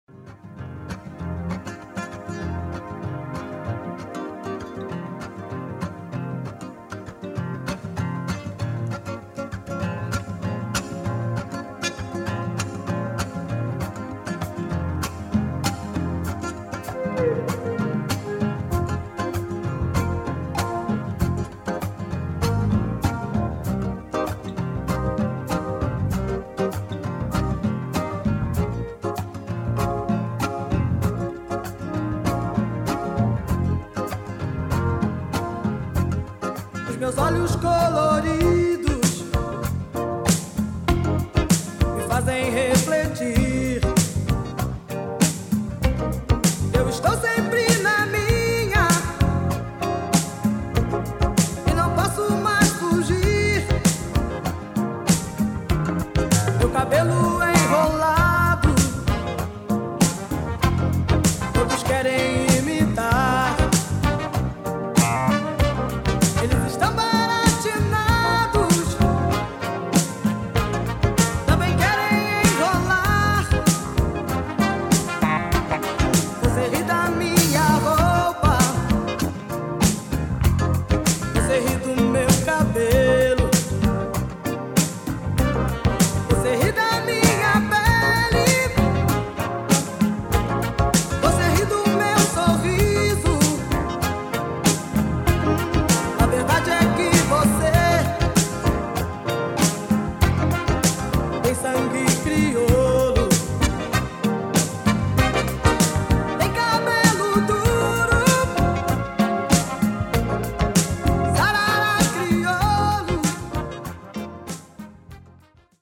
funky Brazilian tune